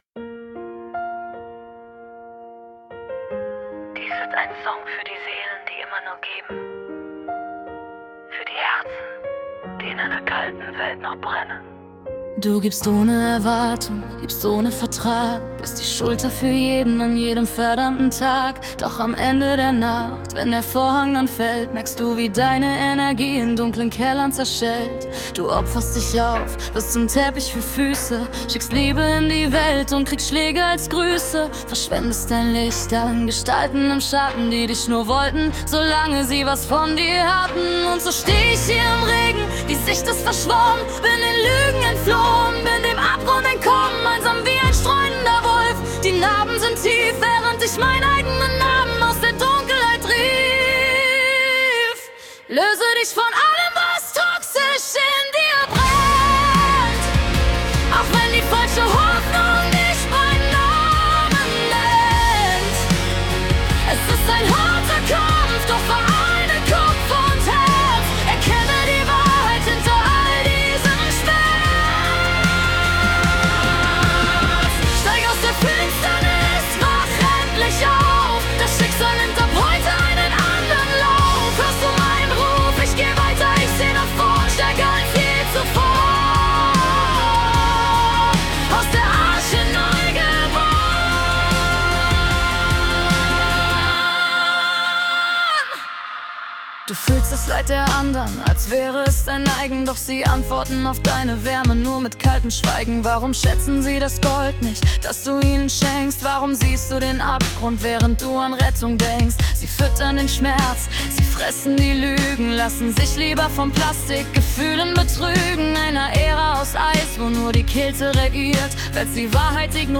Country Version